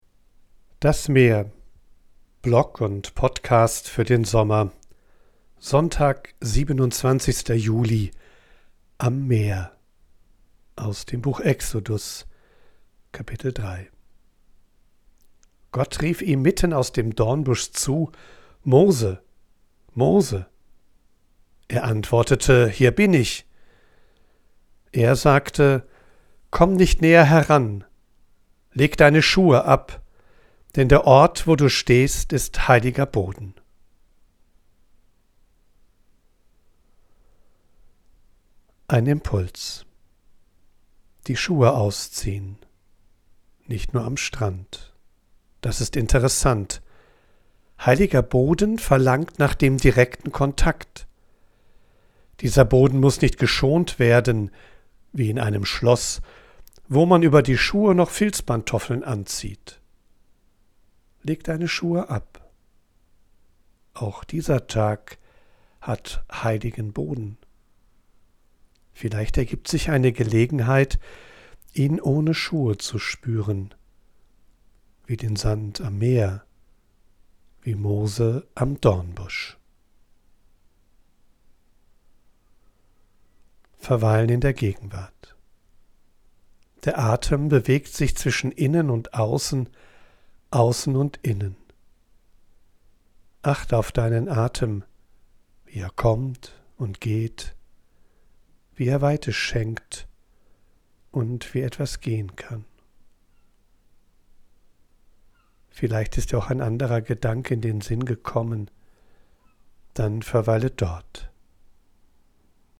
Ich bin am Meer und sammle Eindrücke und Ideen.
von unterwegs aufnehme, ist die Audioqualität begrenzt. Dafür
mischt sie mitunter eine echte Möwe und Meeresrauschen in die